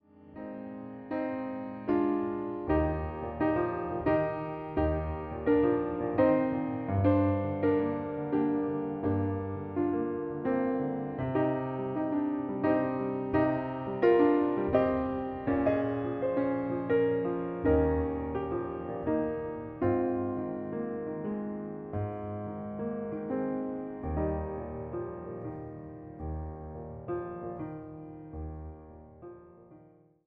様々な情景、抒情を見せるピアノ・ソロの世界が広がるアルバムとなっています。